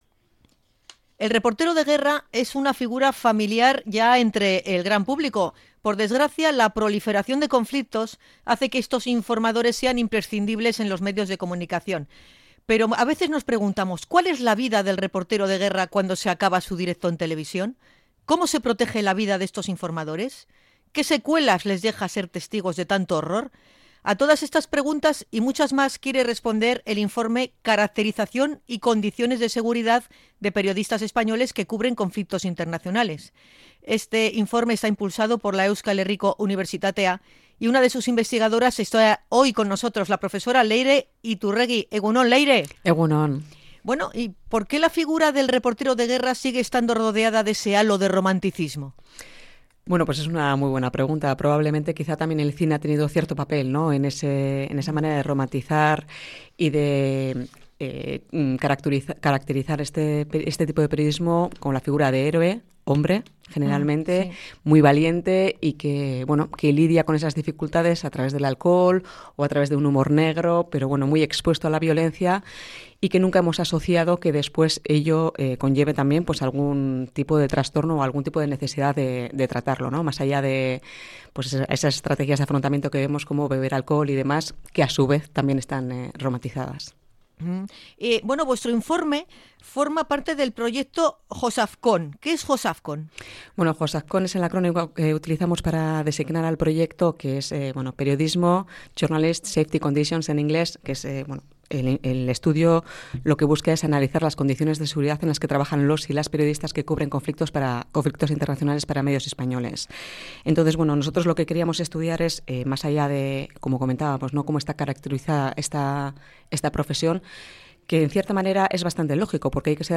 INT.-REPORTEROS-DE-GUERRA.mp3